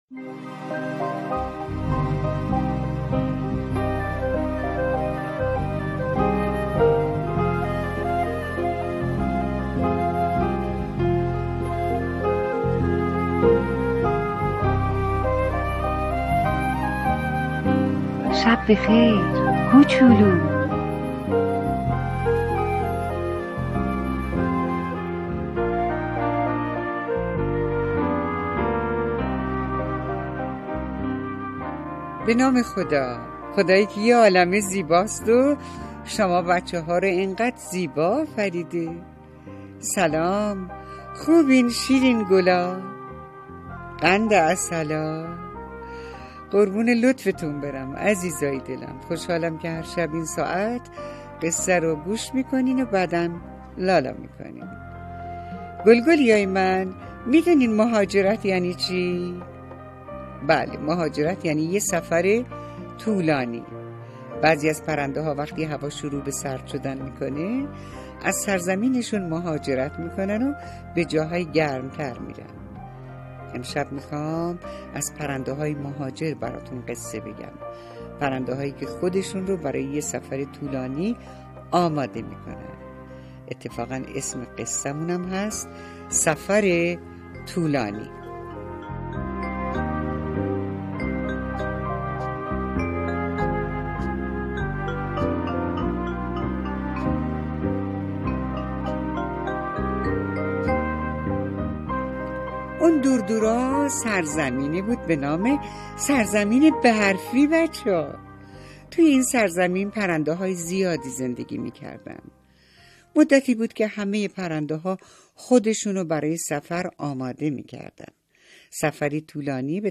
قصه صوتی کودکان دیدگاه شما 1,099 بازدید